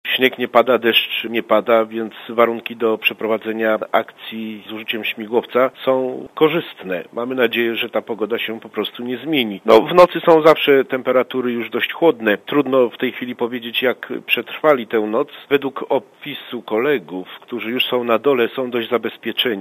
Mówi konsul